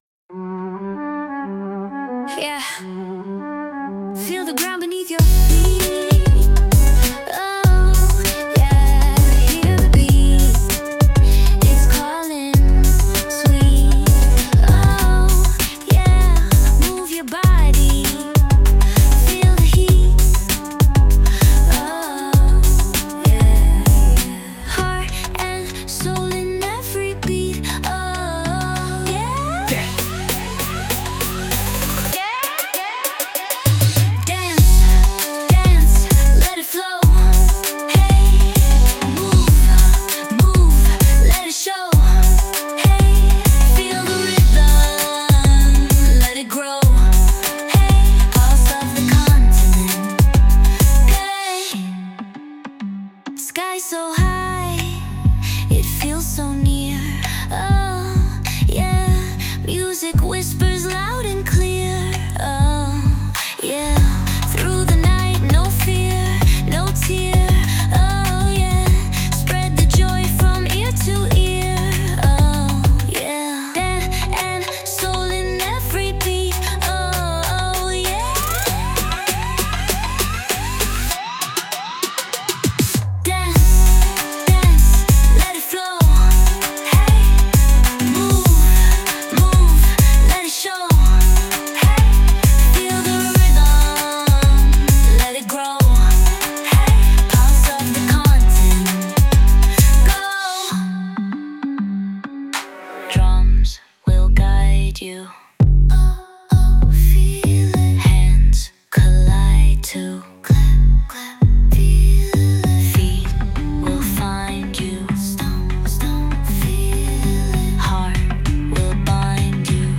テンポはあえて「ゆったりめ」に設定されており、その分、一つ一つのビートに重みとグルーヴがあります。